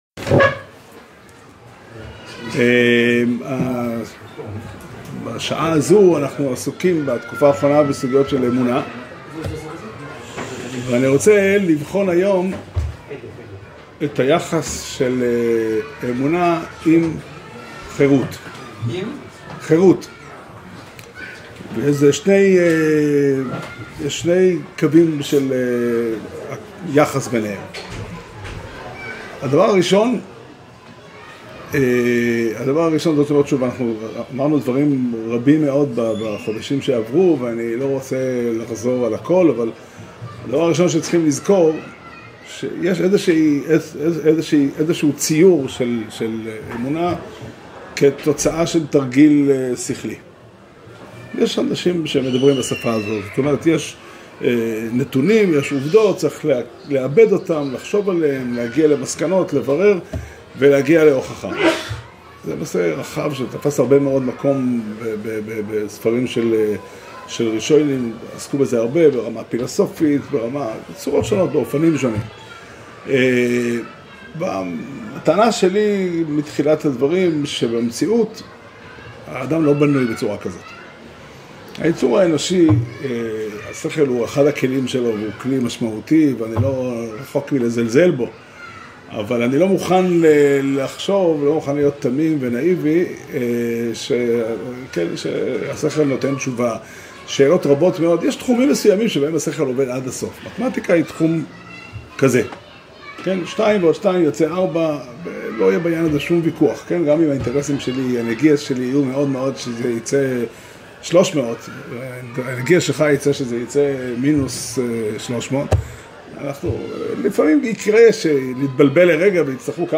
שיעור שנמסר בבית המדרש פתחי עולם בתאריך ה' חשוון תשפ"ה